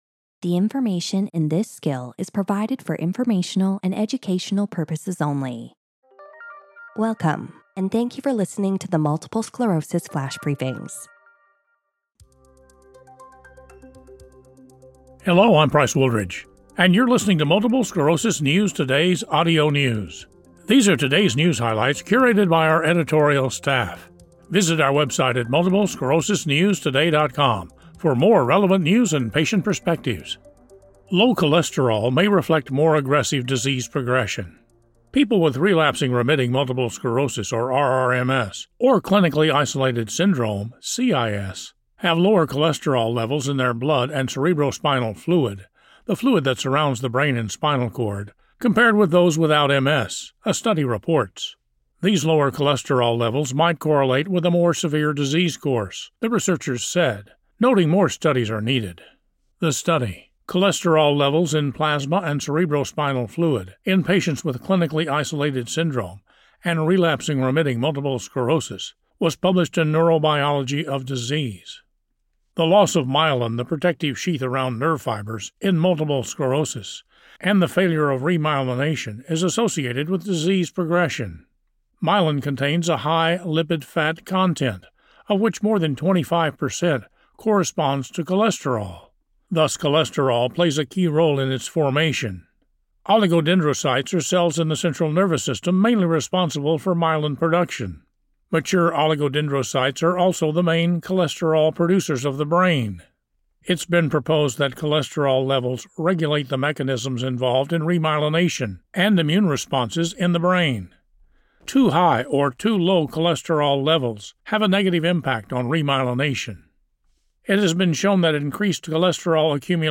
reads a news article reporting that lower cholesterol levels in blood and cerebrospinal fluid might correlate with a more severe disease course in multiple sclerosis patients.